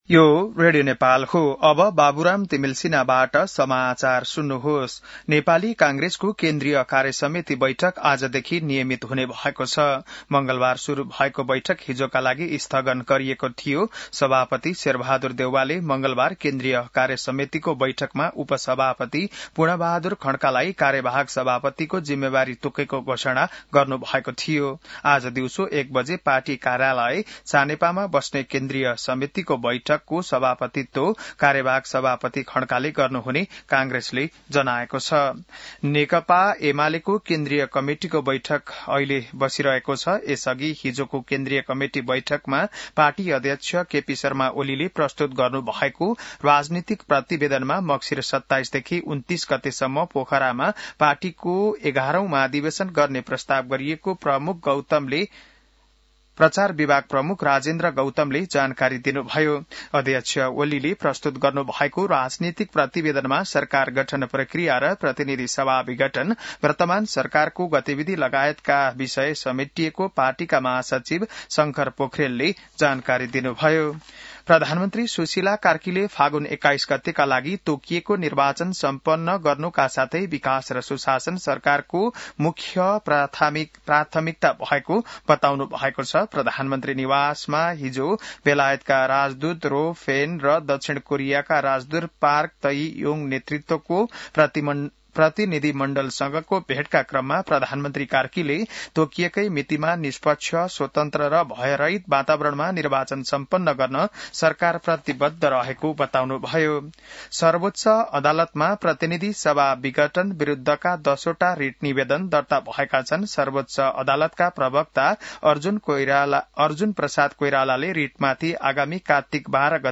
बिहान १० बजेको नेपाली समाचार : ३० असोज , २०८२